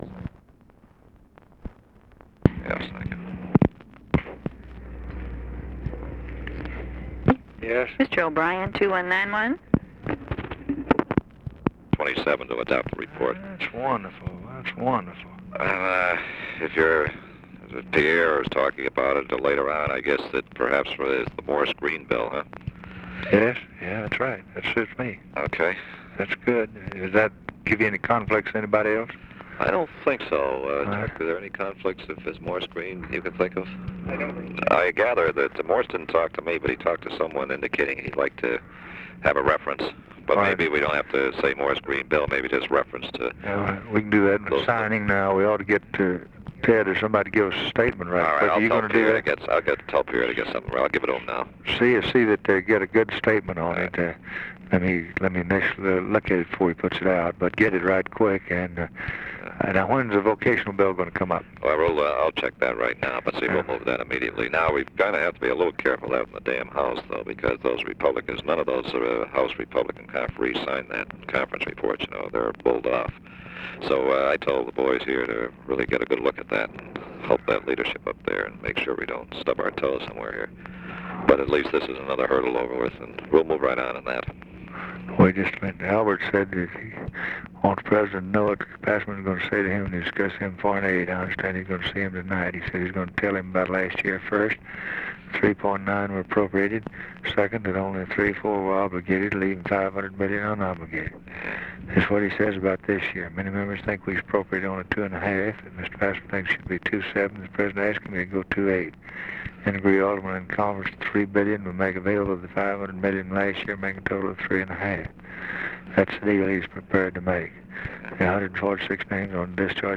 Conversation with LARRY O'BRIEN, December 10, 1963
Secret White House Tapes